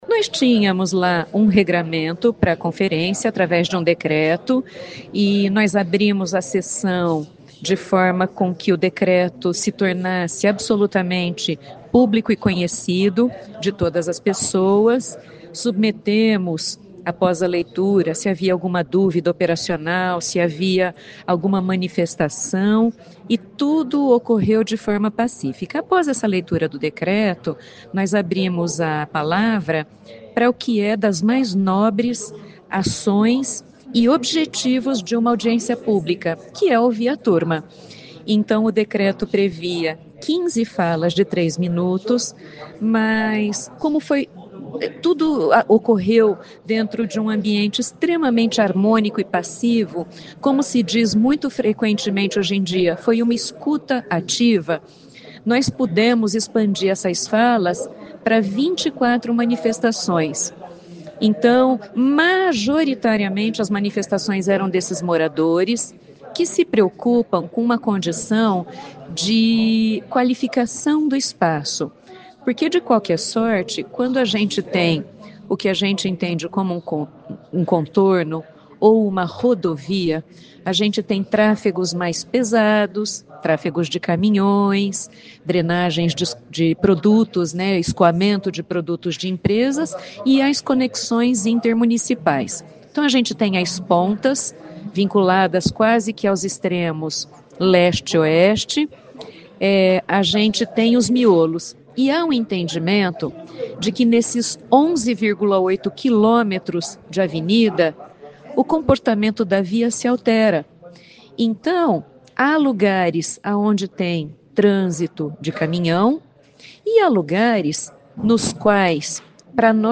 A audiência foi presidida pela secretária Tânia Galvão Verri, diretora-presidente do Instituto de Pesquisas e Planejamento de Maringá (Ipplam).